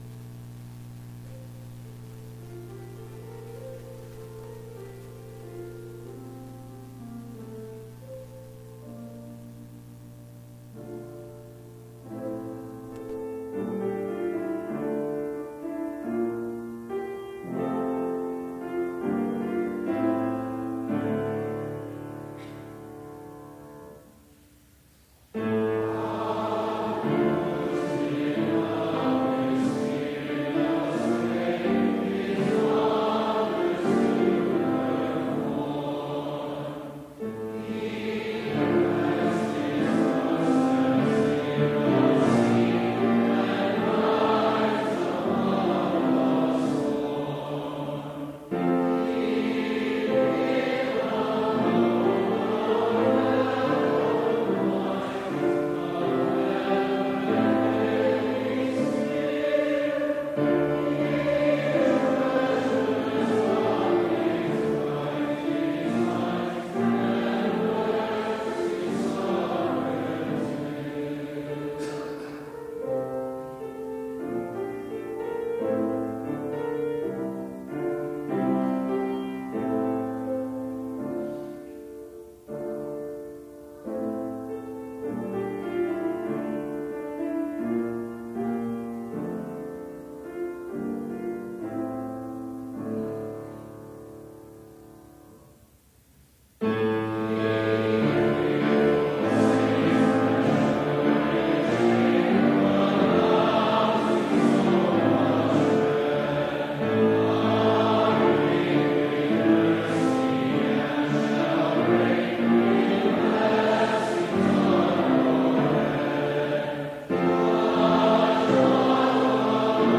Complete service audio for Chapel - November 20, 2018